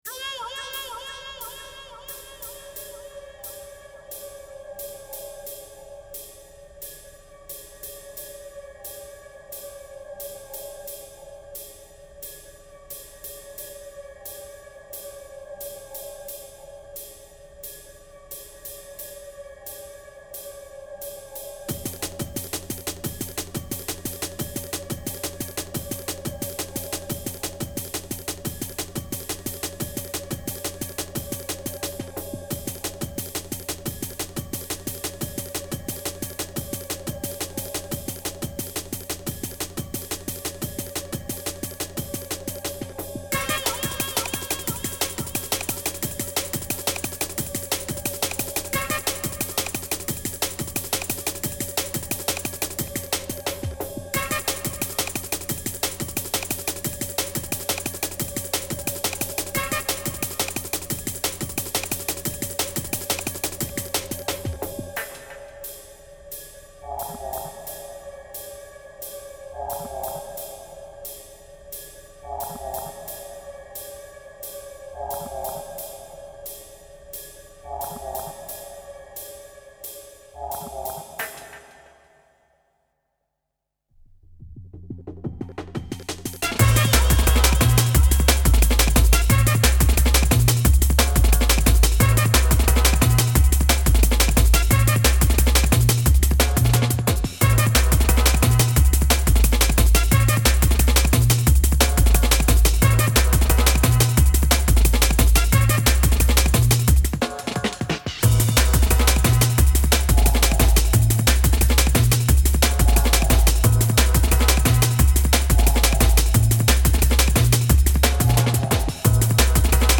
Demomix recorded end of July 2005